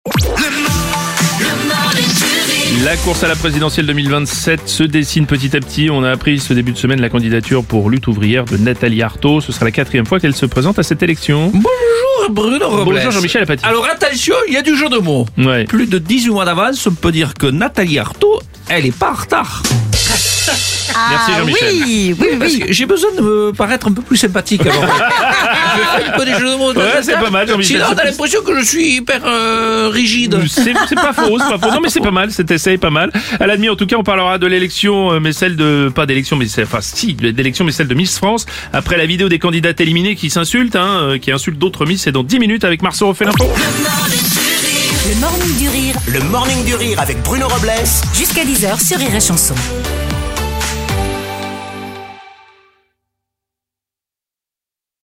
L’imitateur
en direct à 7h30, 8h30, et 9h30.